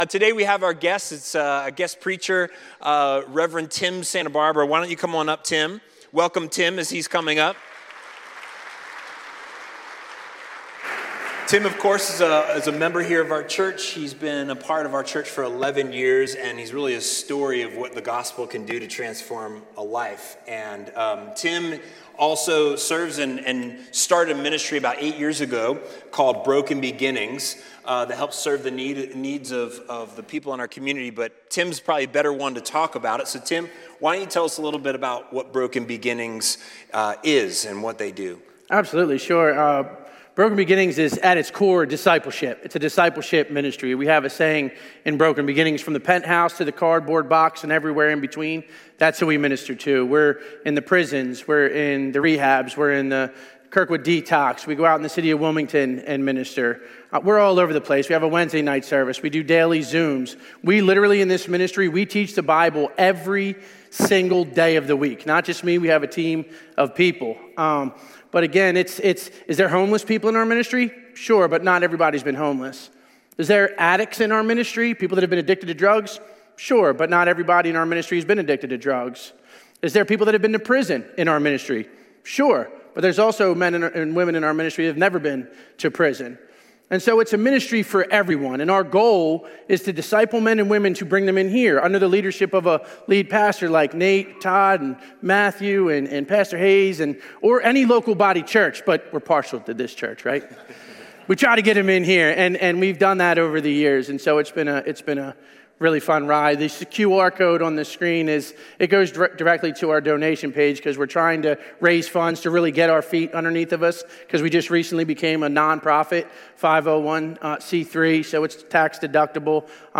Watch previously recorded Sunday sermons.